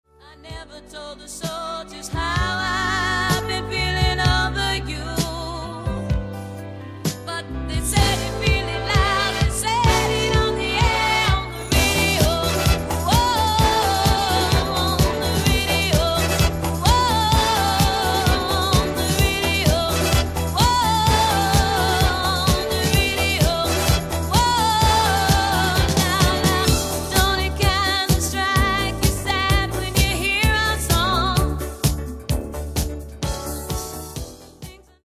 Genere:   Disco | Soul | Funk